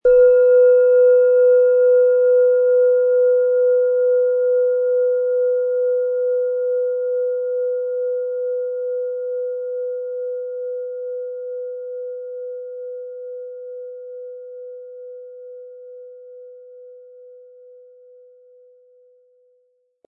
Handgearbeitete Delfin Klangschale.
Der gratis Klöppel lässt die Schale wohltuend erklingen.
MaterialBronze